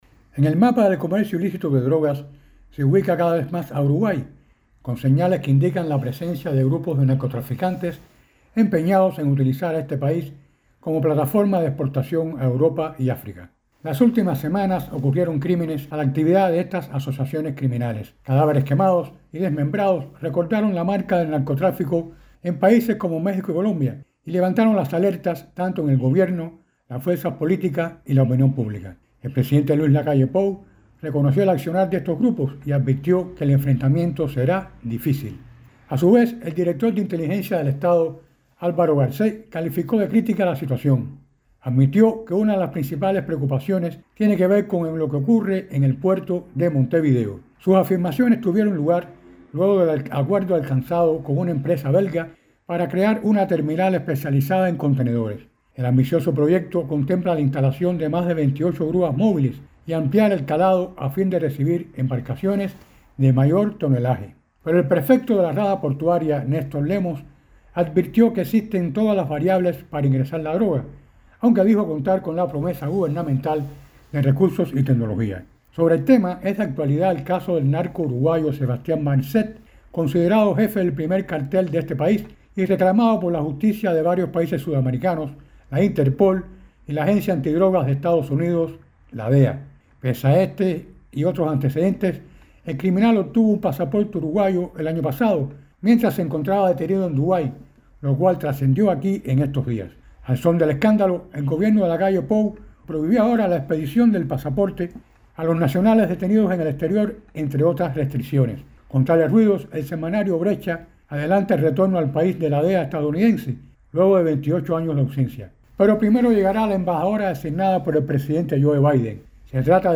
desde Montevideo